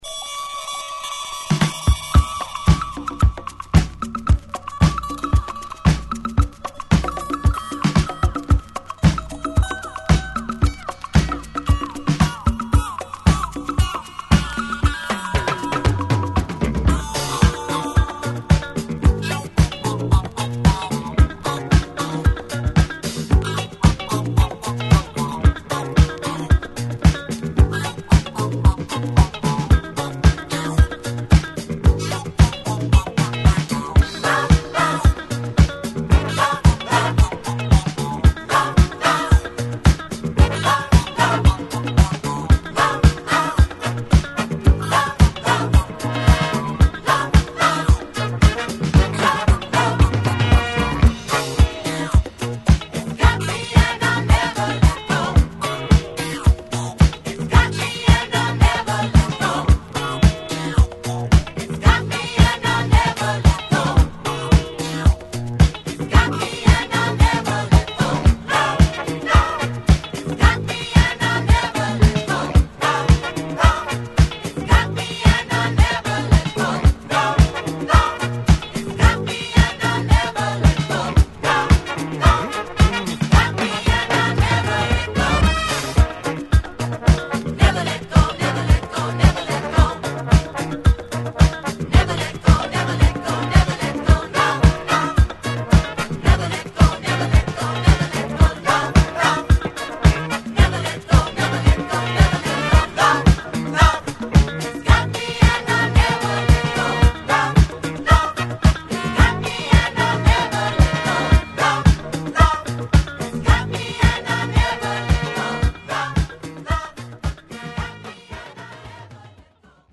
・ 45's SOUL / FUNK / DISCO / JAZZ / ROCK